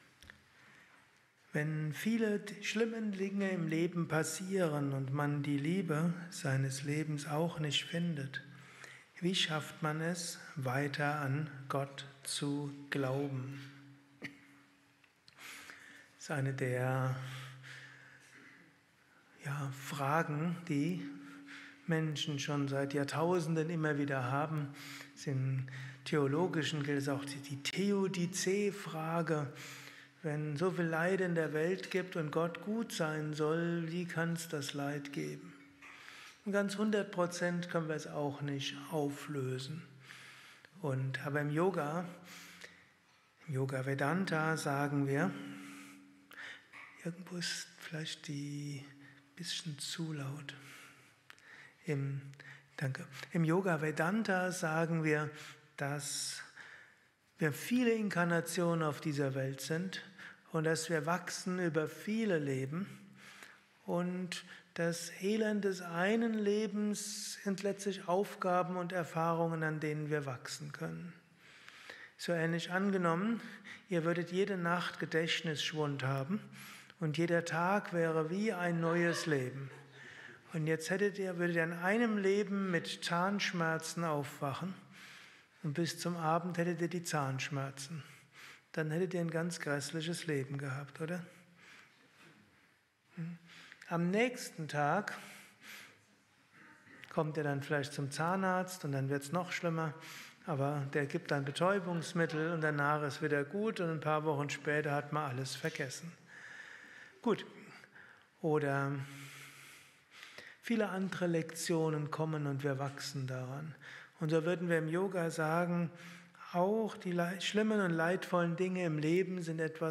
Kurzvorträge
gehalten nach einer Meditation im Yoga Vidya Ashram Bad Meinberg.